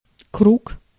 Ääntäminen
US : IPA : [ˈæv.ə.ɹɪdʒ]